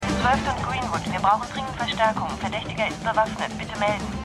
Renegade_2x09_Polizeifunk.mp3